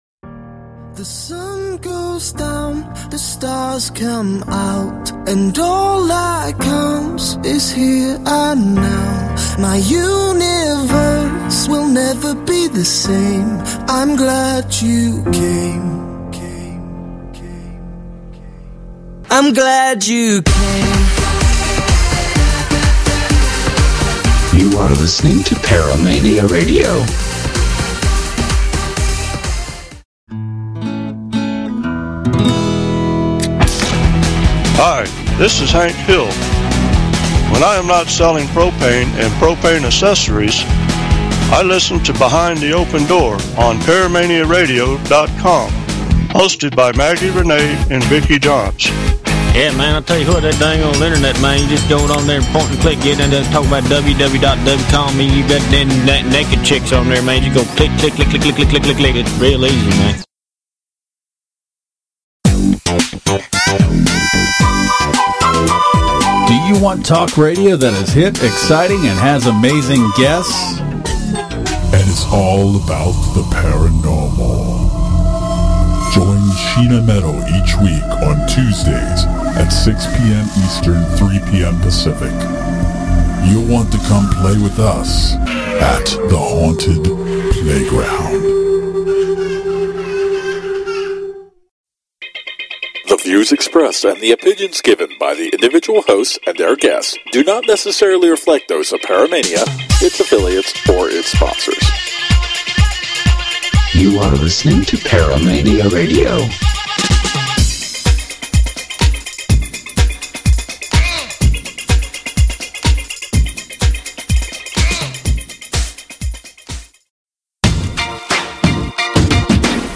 The actual interview starts at 2:58 into this audio clip.